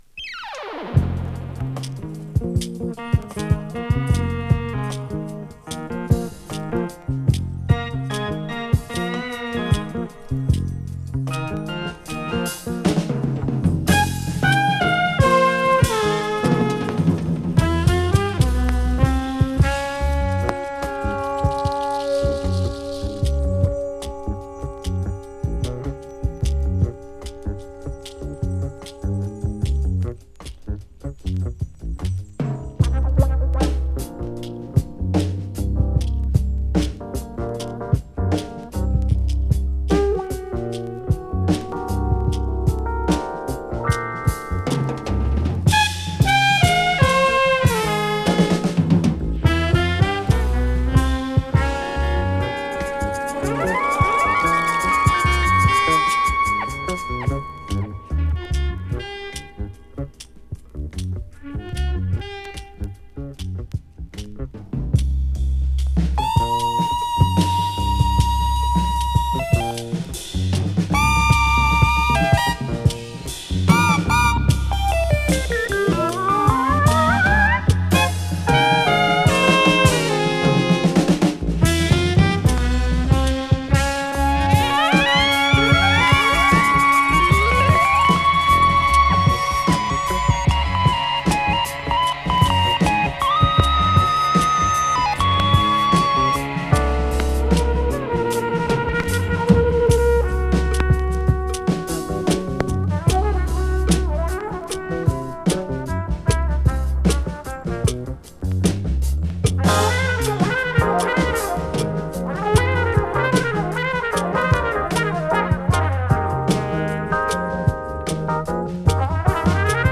重厚なジャズファンク・フュージョン、トライバルなパーカッショングルーヴなど全曲必聴！